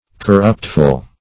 Search Result for " corruptful" : The Collaborative International Dictionary of English v.0.48: Corruptful \Cor*rupt"ful\ (-f?l), a. Tending to corrupt; full of corruption.